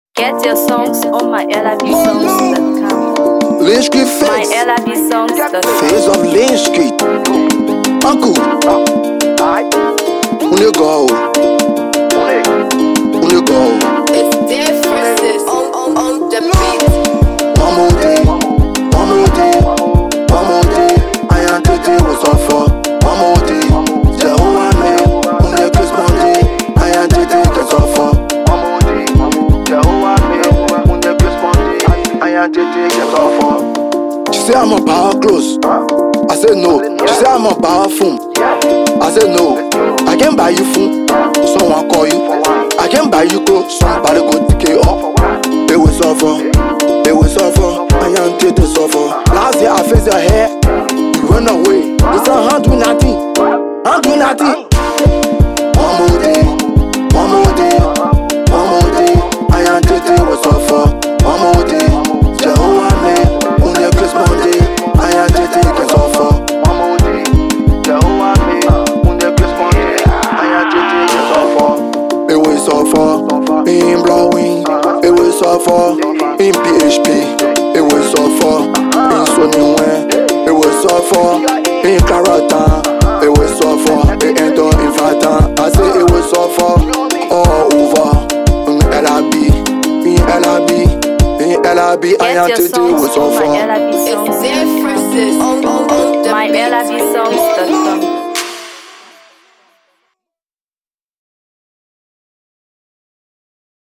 Afro PopGbema